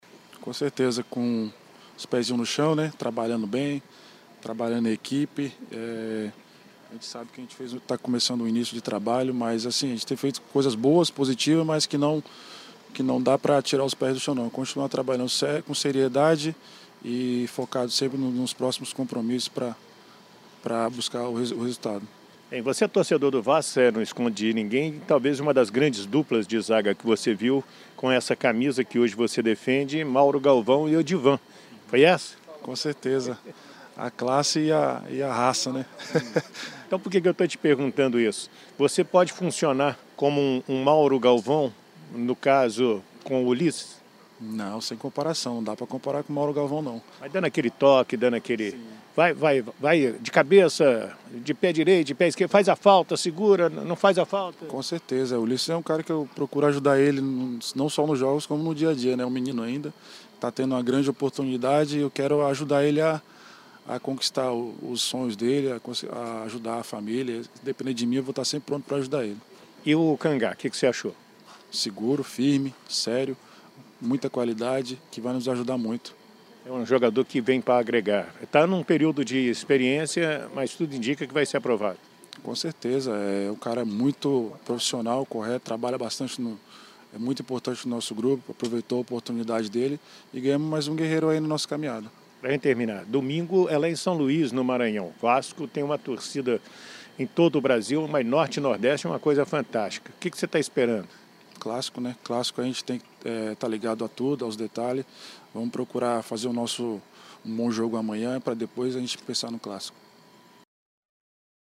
entrevista coletiva